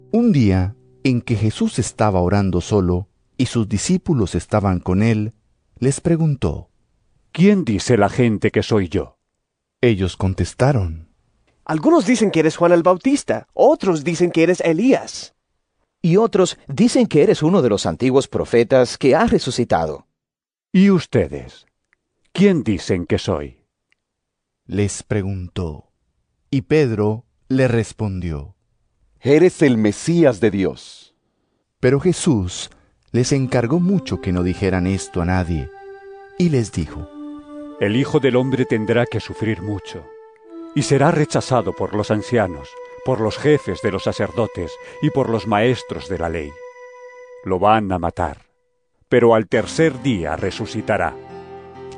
Lc 9 18-22 EVANGELIO EN AUDIO